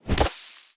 Sfx_creature_pinnacarid_hop_slow_03.ogg